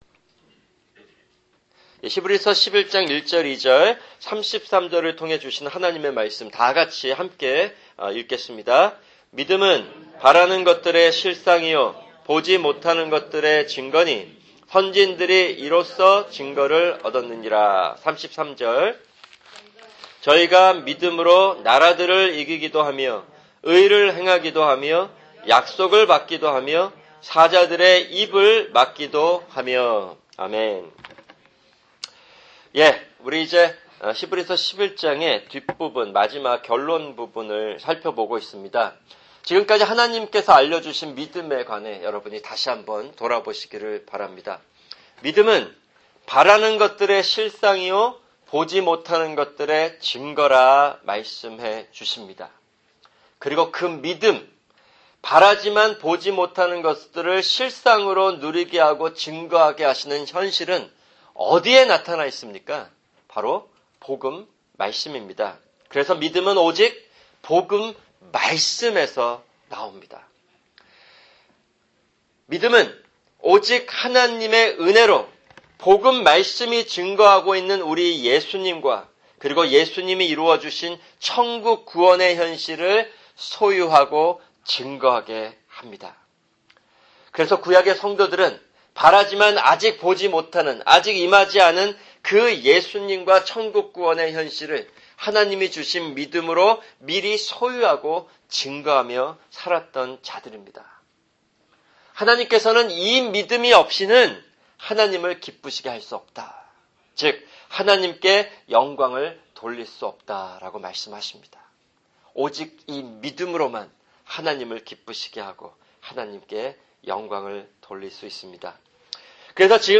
[금요 성경공부] 히브리서11장(30) 11:33